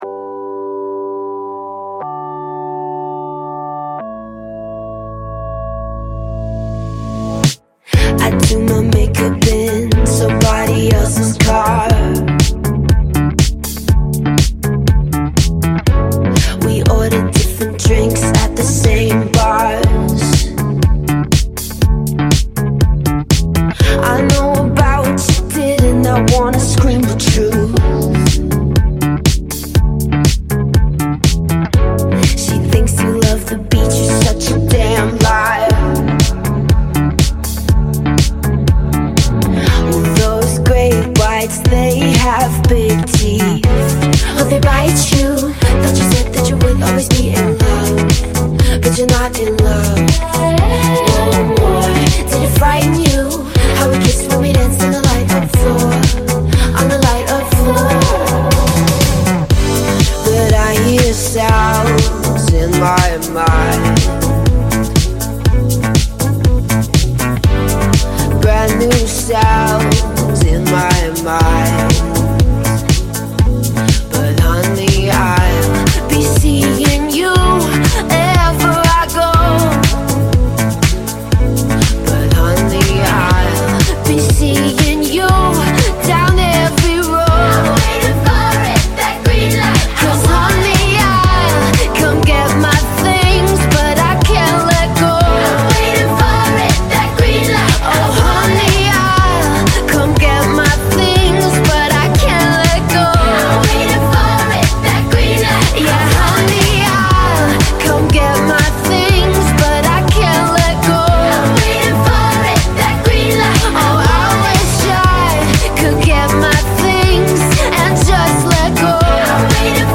BPM121
Audio QualityCut From Video